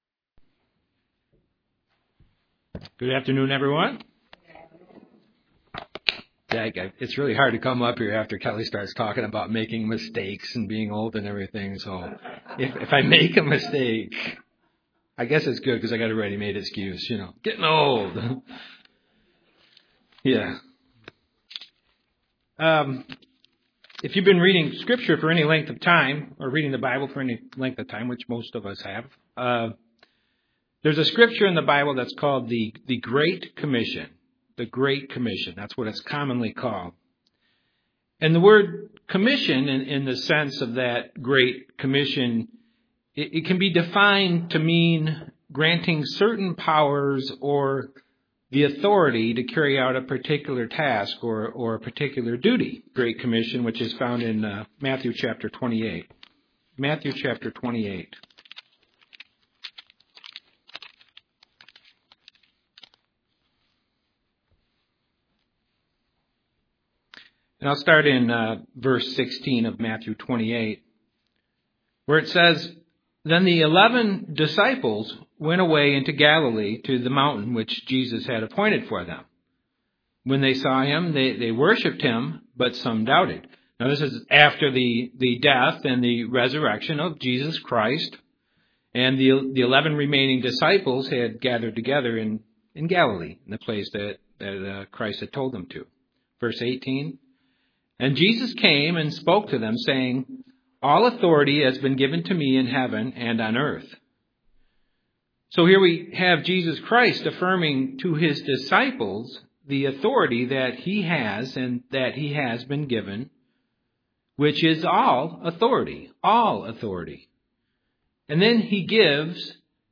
Given in Grand Rapids, MI
UCG Sermon Studying the bible?